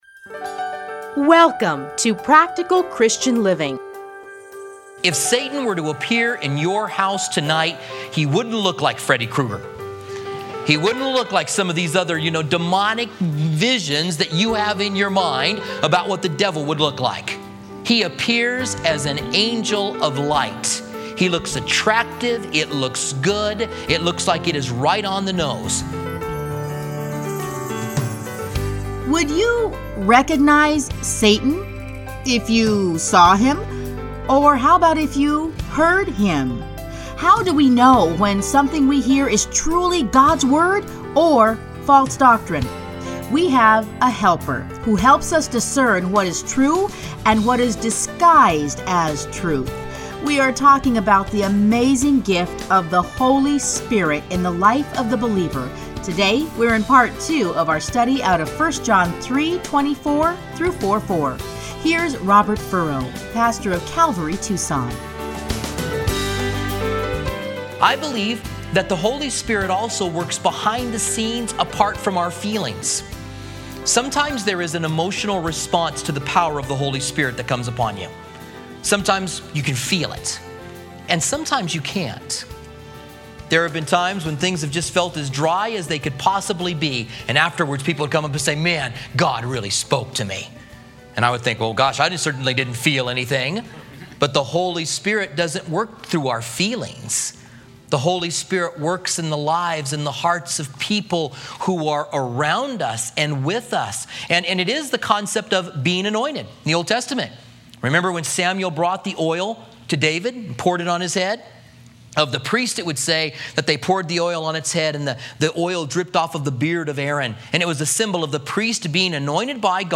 Listen here to his studies in the book of 1 John.